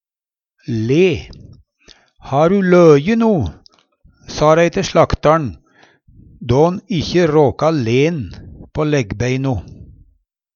le - Numedalsmål (en-US)